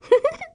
Sound of Princess Peach's giggle from Mario & Luigi: Dream Team
MLDT_-_Peach's_Giggle.oga.mp3